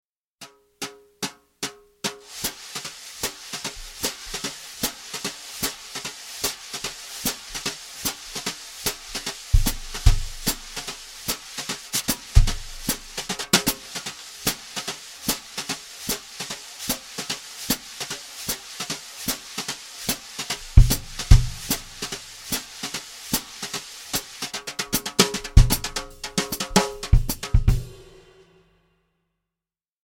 Etude pour Batterie